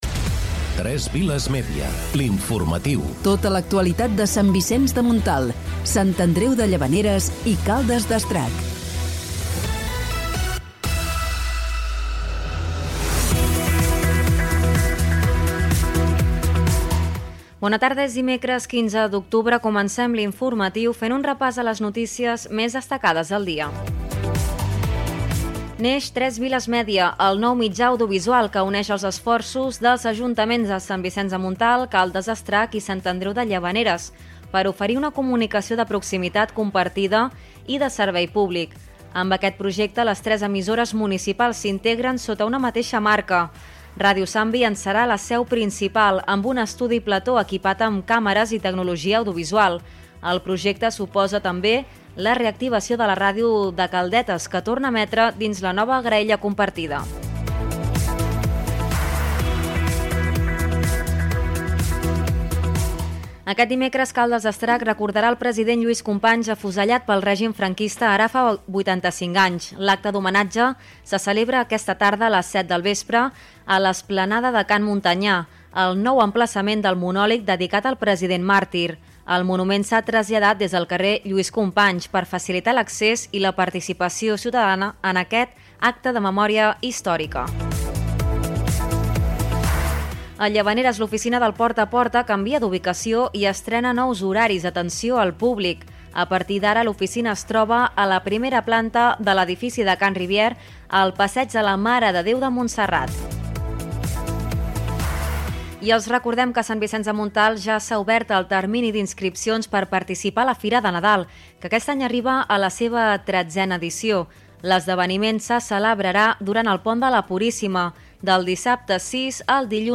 Careta del programa, titulars, informació del naixement del mitjà de comunicació públic 3 Viles Mèdia, homenatge a Lluís Companys a Caldes d'Estrac
Gènere radiofònic Informatiu